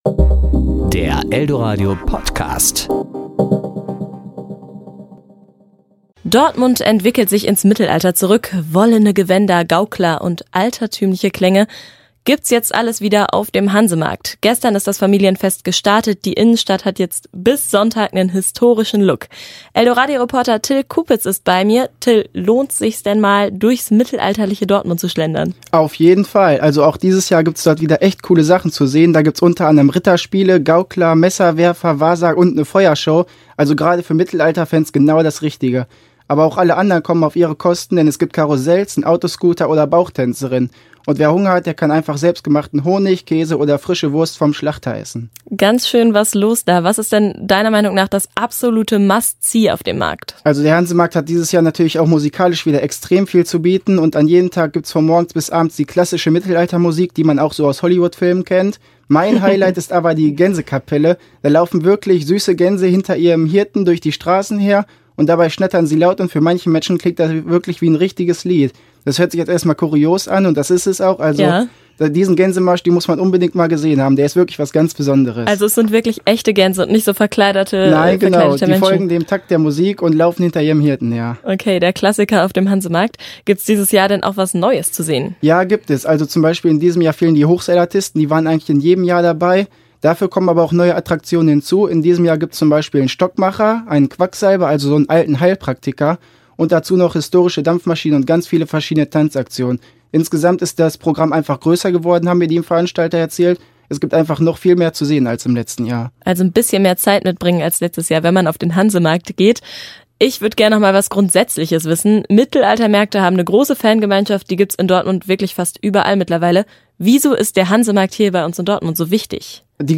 Kollegengespräch  Sendung